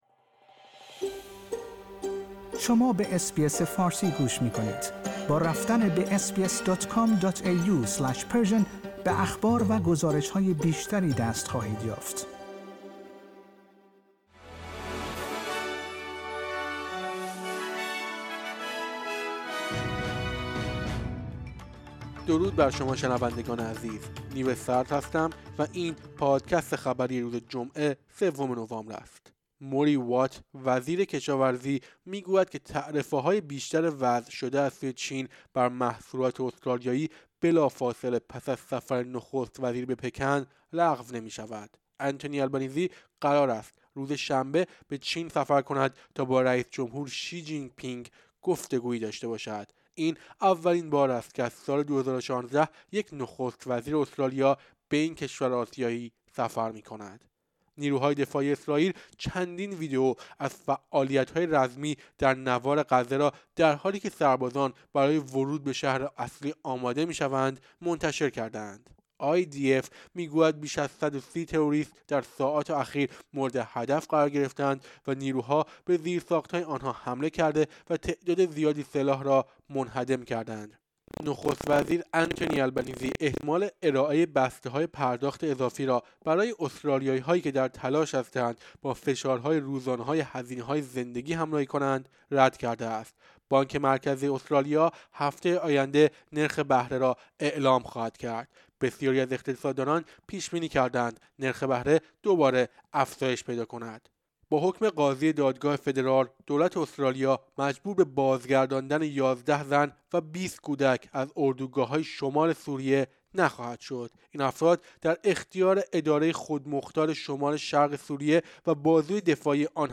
در این پادکست خبری مهمترین اخبار استرالیا و جهان در روز جمعه سوم نوامبر ۲۰۲۳ ارائه شده است.